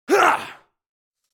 دانلود آهنگ نبرد 1 از افکت صوتی انسان و موجودات زنده
جلوه های صوتی